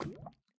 drip_lava3.ogg